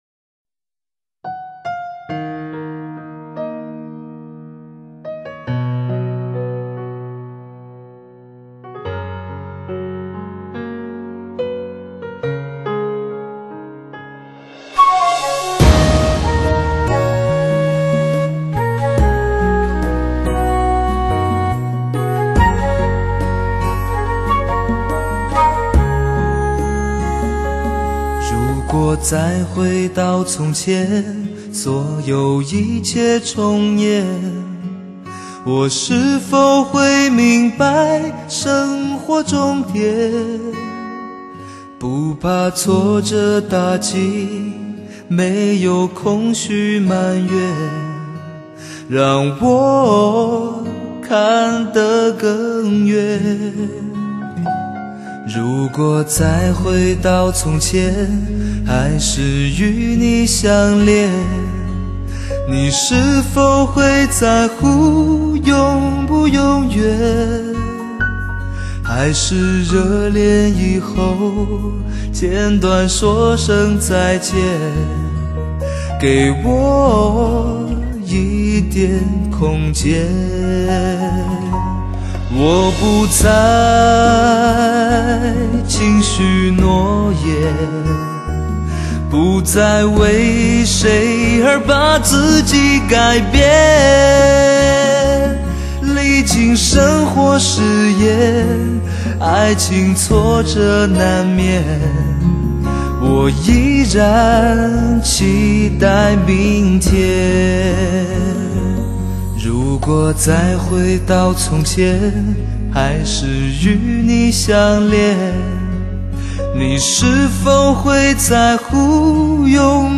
男声发烧碟少有的佳作之一。收录众多男声演绎的精品，经典老歌。
既保持了原作品风格的编配，也加入不少新颖发烧元素，令你听出耳油。